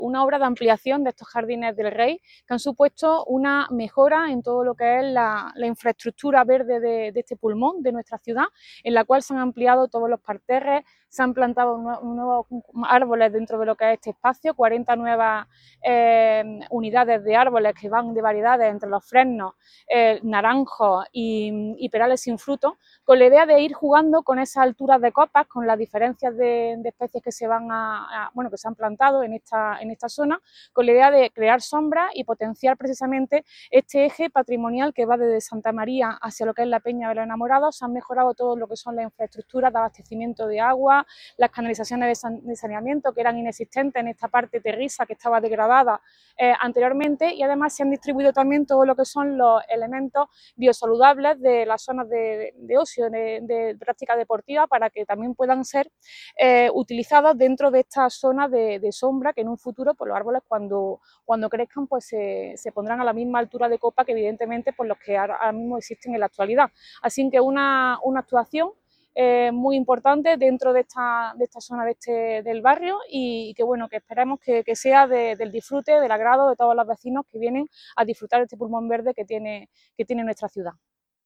El alcalde de Antequera, Manolo Barón, y la teniente de alcalde delegada de Obras, Teresa Molina, han visitado los conocidos como Jardines del Rey, en la confluencia del barrio de Santiago y la barriada Los Remedios, una vez que han terminado las obras de ampliación, reordenación y mejora de los mismos.
Cortes de voz